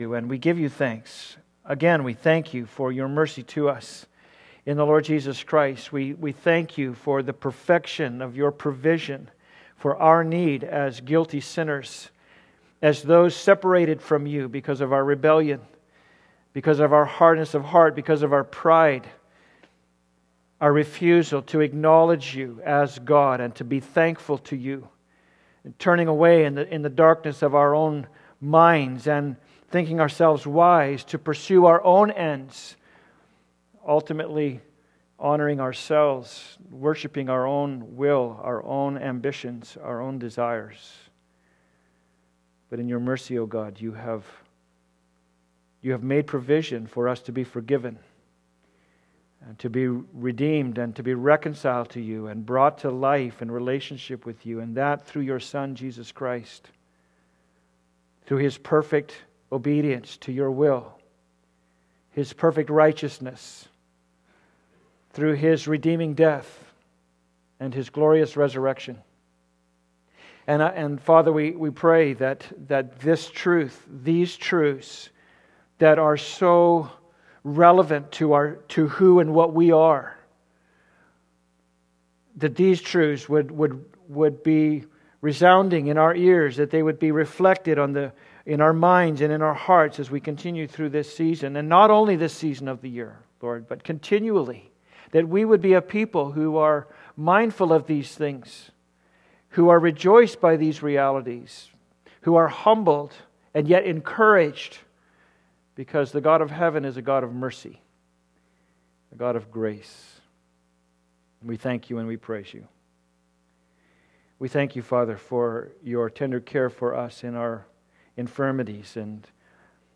Passage: Various Texts Service Type: Sunday Service « Who Do You Say I Am?